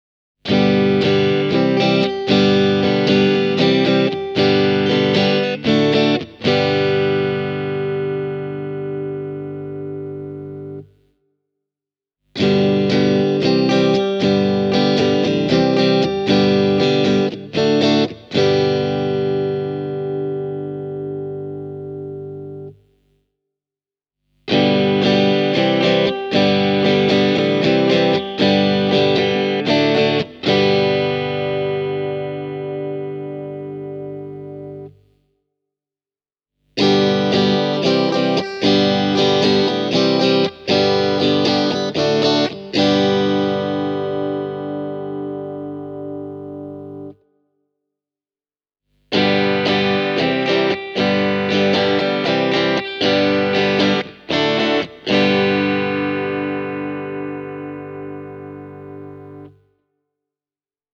The neck and (reverse-wound) middle pickup use Alnico III magnets for a bright sound with a sharp attack and a fantastically dynamic response. The bridge pickup is wound a little hotter and comes loaded with more powerful Alnico V magnets for a more muscular tone.